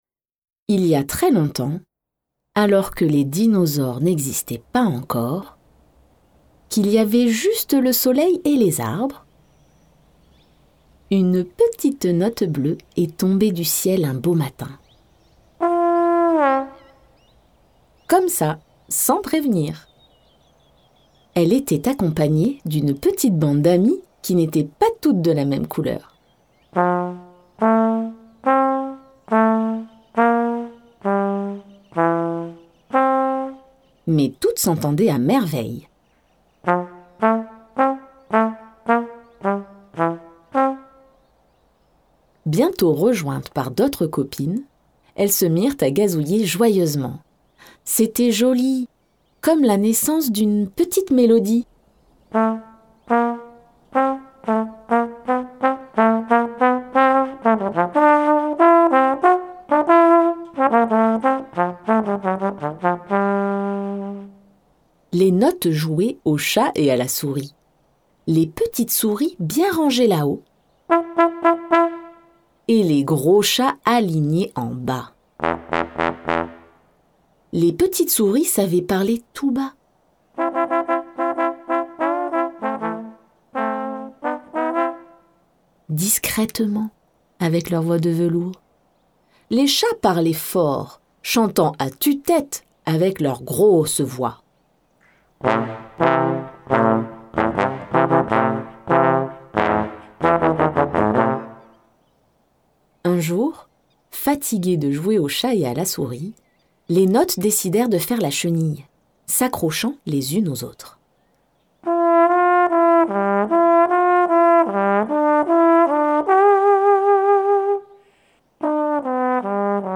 Livre cd
Une voix bien sûr !